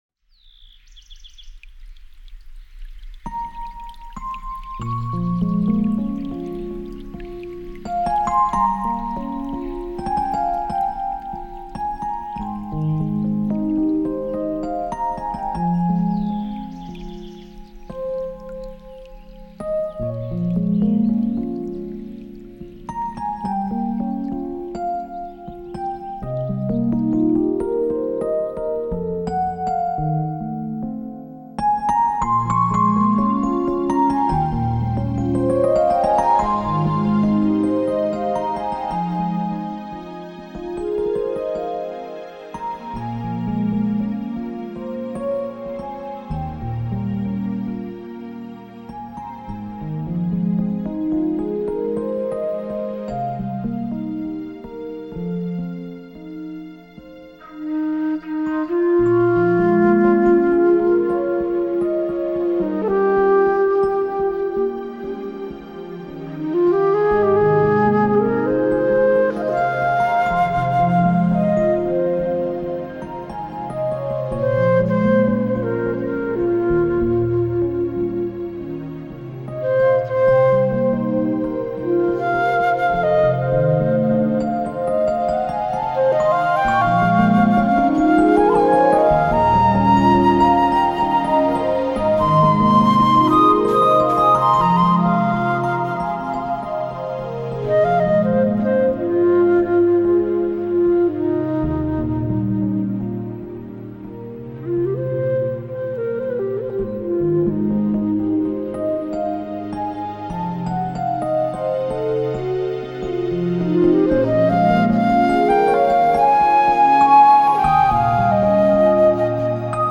专辑歌手：纯音乐
你都不妨让这舒缓而甜美的音乐将你包容！